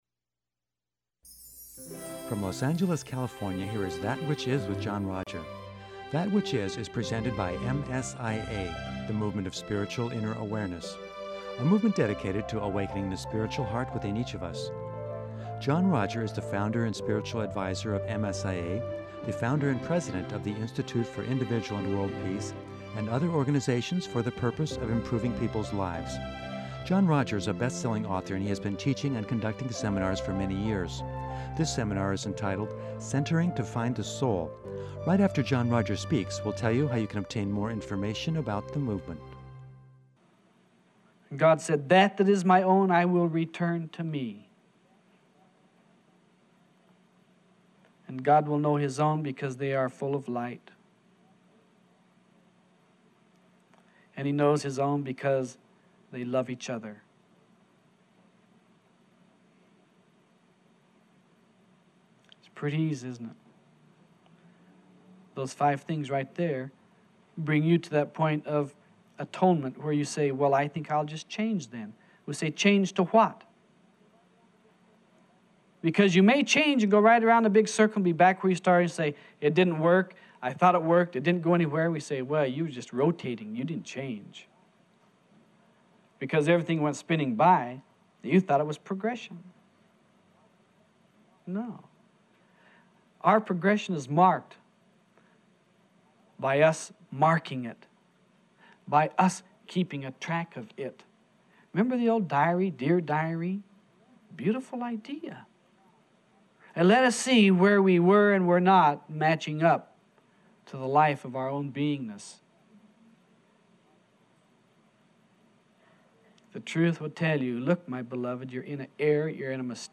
In part 2 of this seminar